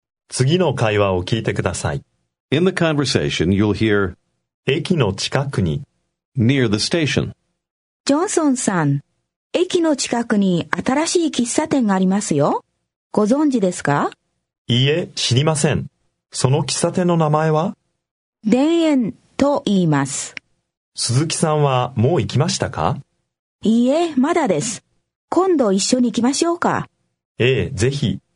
Аудио курс для самостоятельного изучения японского языка.